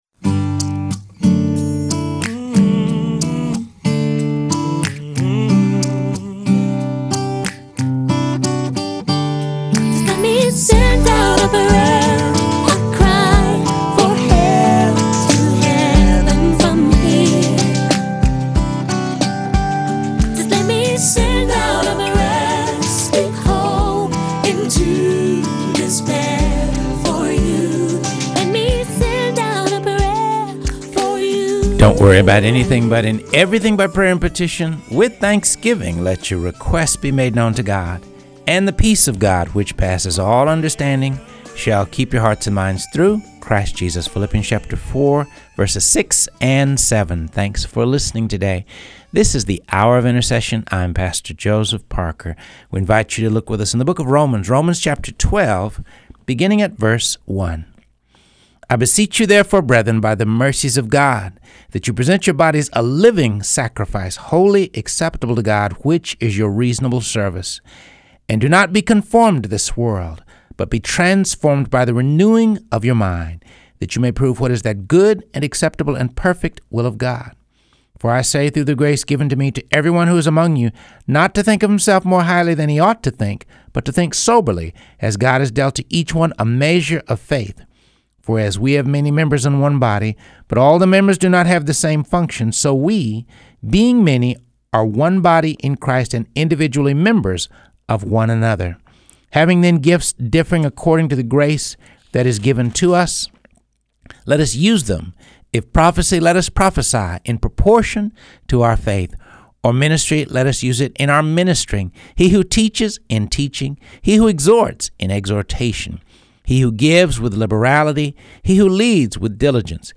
Reading through the Word of God | Episode 55